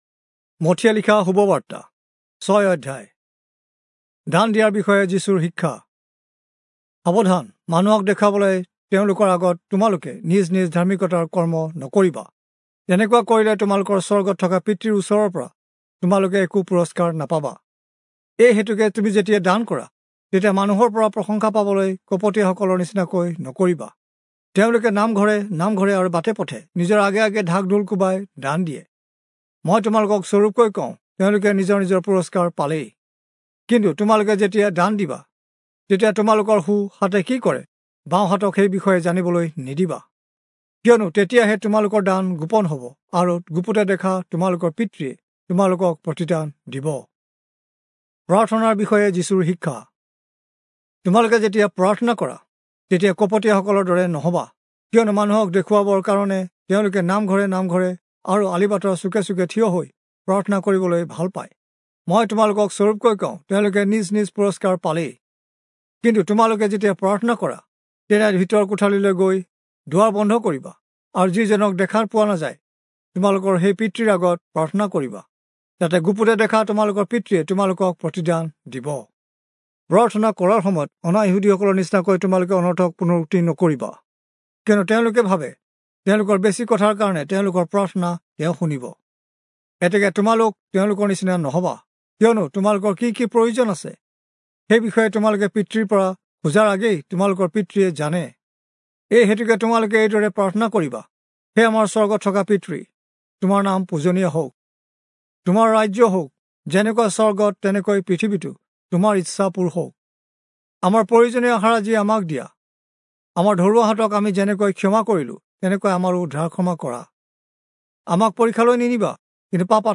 Assamese Audio Bible - Matthew 26 in Orv bible version